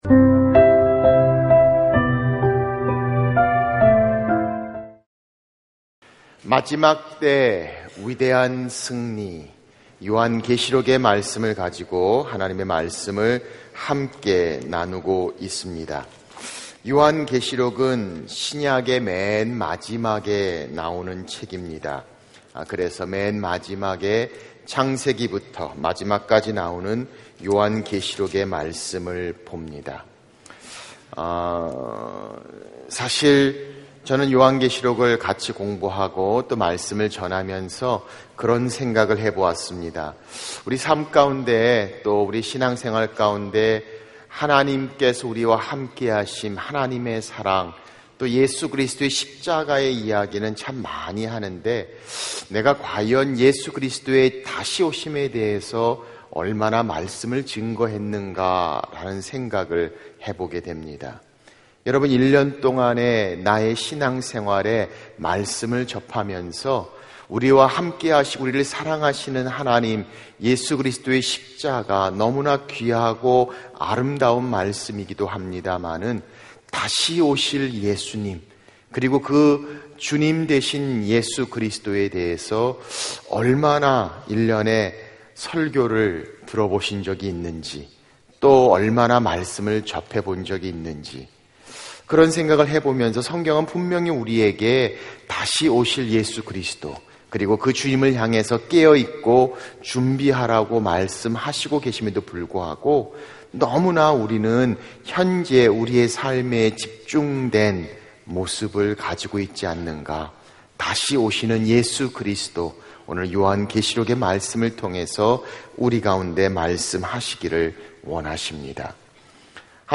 설교 | (2) 요한이 본 그리스도 Ⅱ